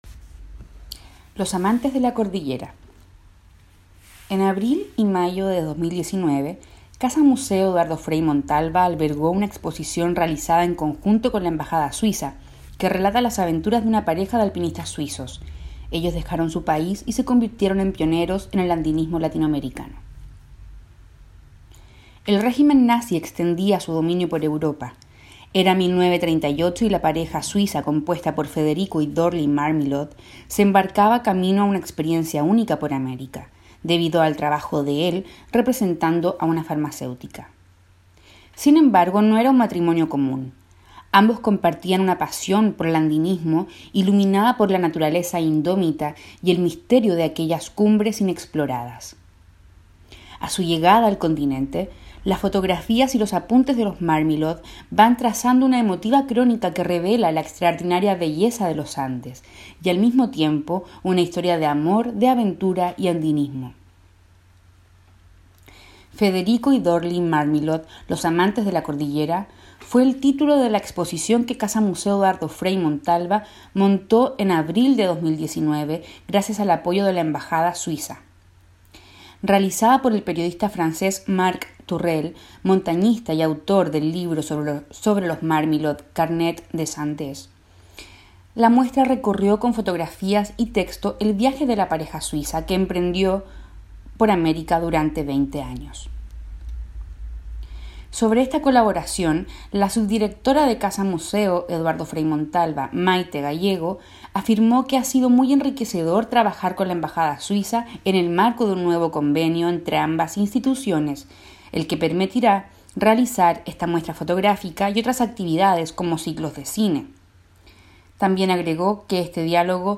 Audio artículo